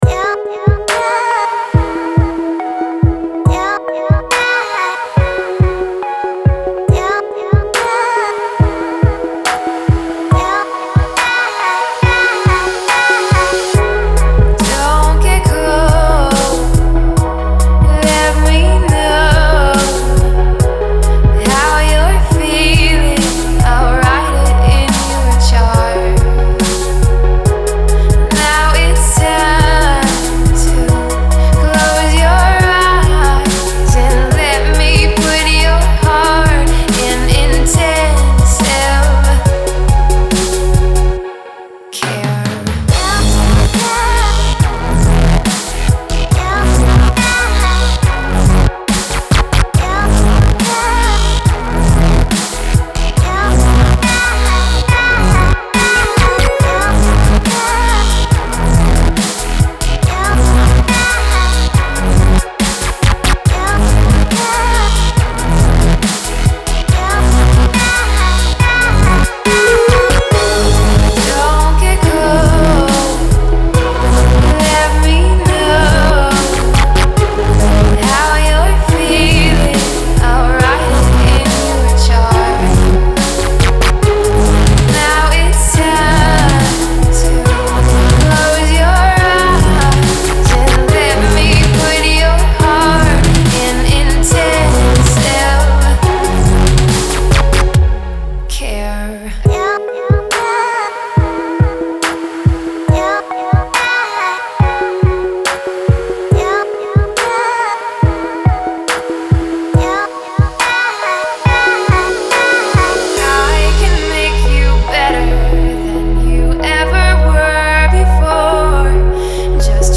Дабстеп